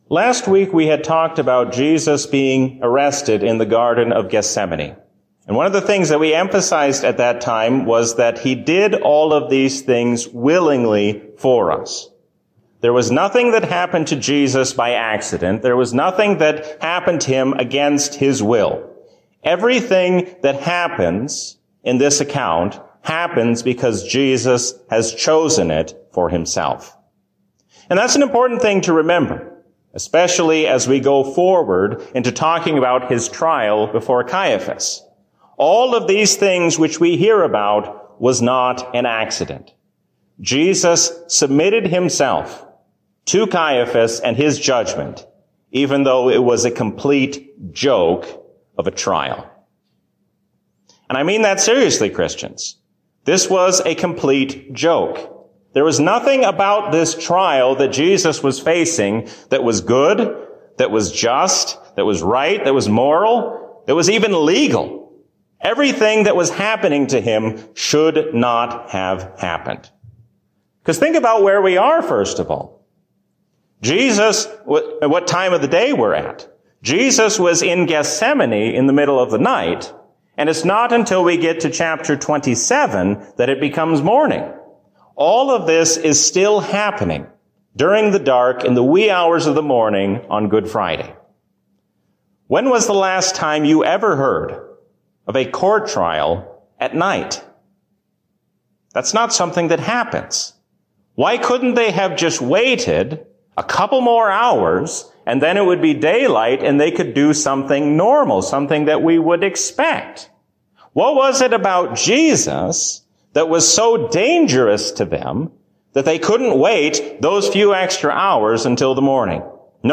A sermon from the season "Lent 2021." God gives us hope in His Son even when the future seems uncertain.